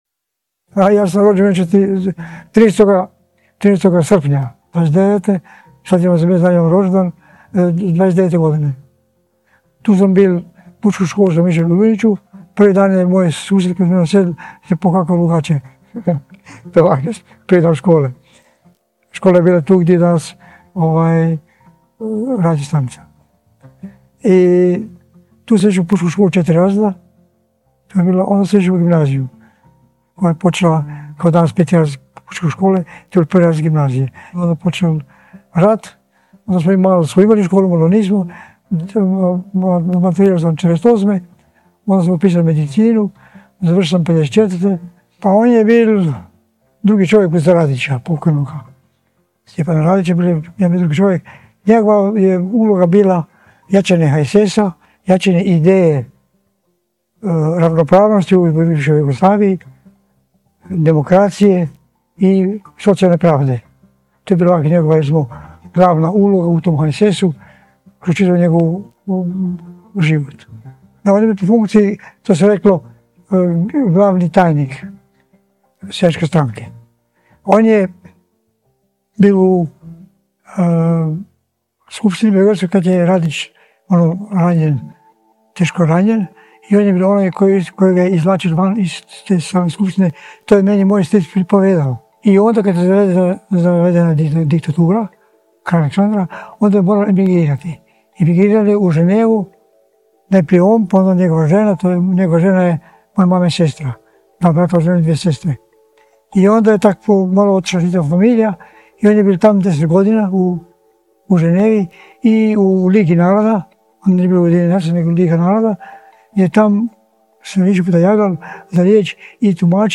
Govor koji nije pripreman za snimanje, ali je ostao zabilježen.
Govor je mjestimice tih, misli se znaju vraćati, rečenice zastaju.
Sve se izgovara u istom tonu — kao dio života, ne kao njegova definicija.
Slušajući snimku, jasno je da se ne sluša samo sadržaj, nego i način govora. Pauze, zastajkivanja, tišina između rečenica — sve je dio tog glasa. Glasa koji ne želi ostaviti poruku, nego ostaje ono što jest.
Originalni audio zapis razgovora